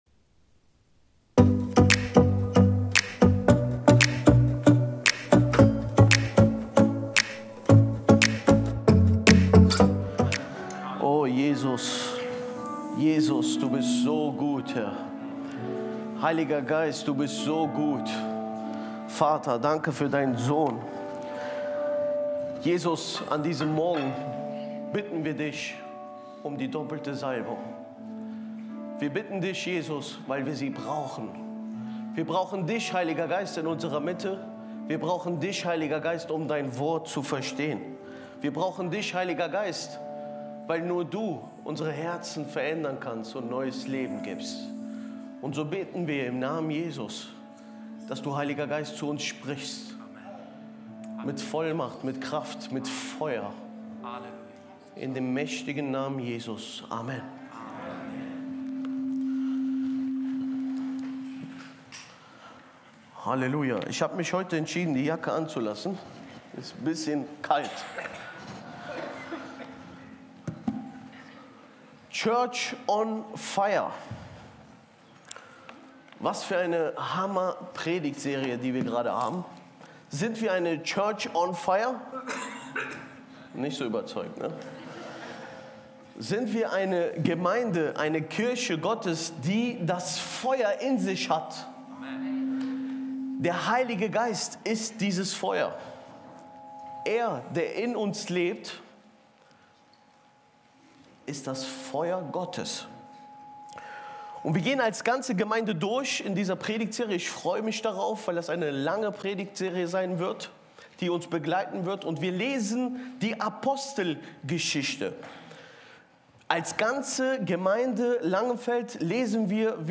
Live-Gottesdienst aus der Life Kirche Langenfeld.
Kategorie: Sonntaggottesdienst